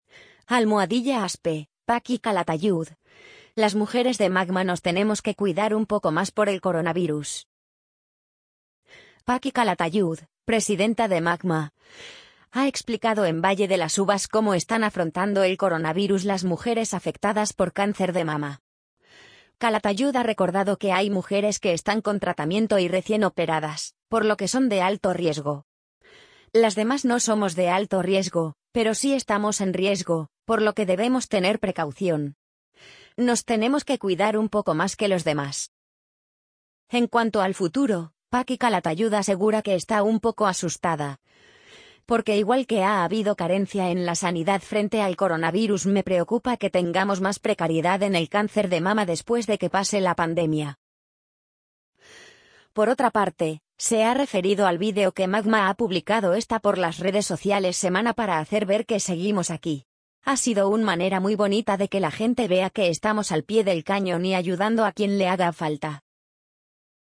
amazon_polly_42220.mp3